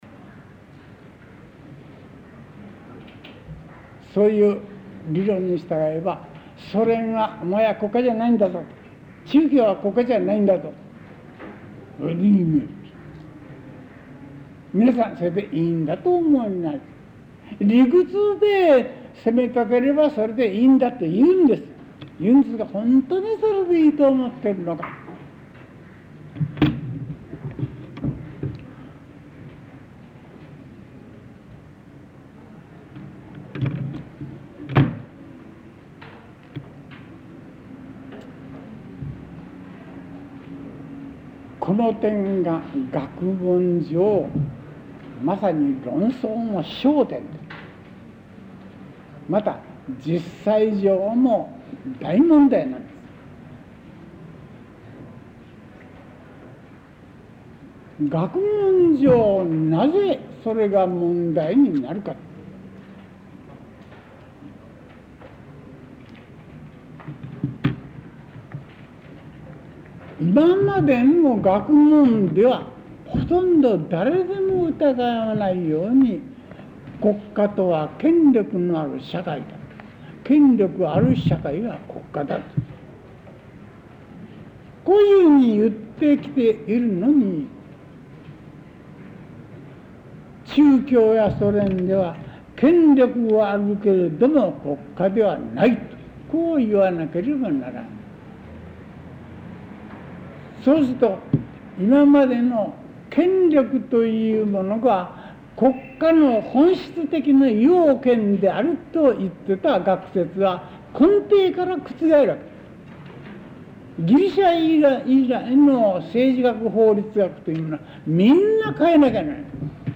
講義録音テープ 9 | NDLサーチ | 国立国会図書館
テープ種別: Sony-SuperA Type5-90